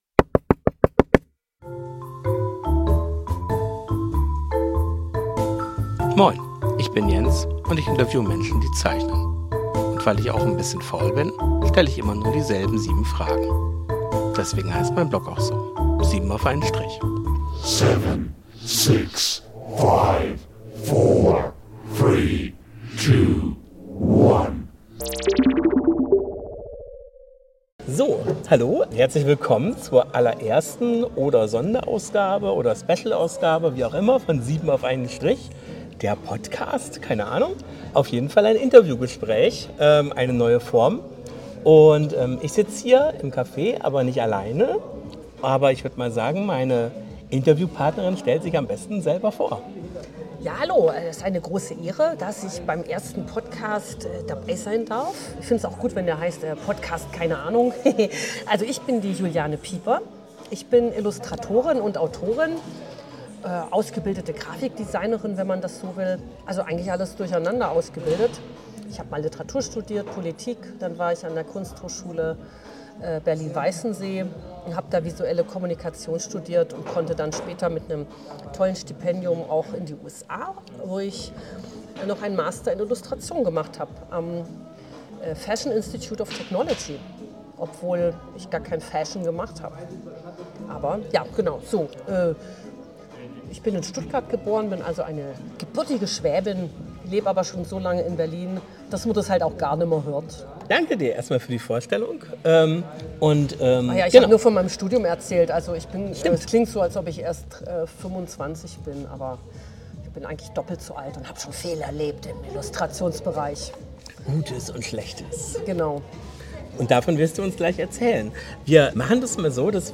SIEBEN AUF EINEN STRICH – das sind 7 Fragen an Comic-Zeichner*innen und Illustrator*innen.